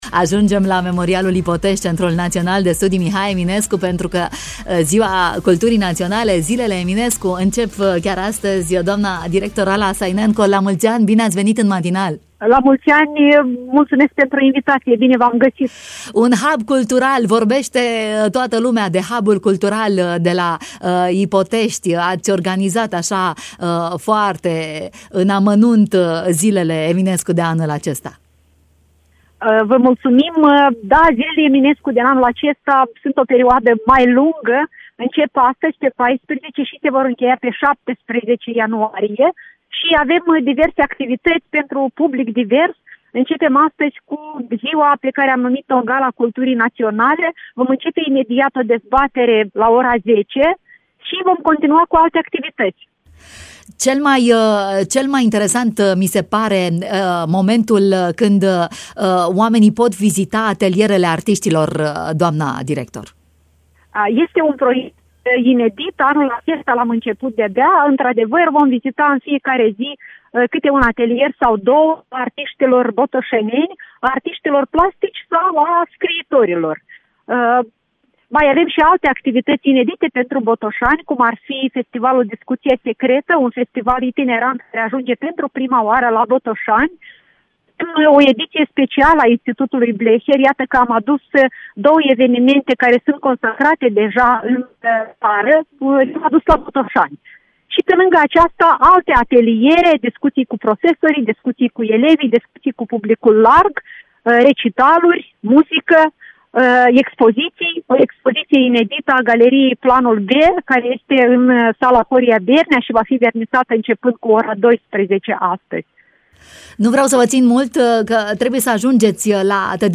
în direct în matinal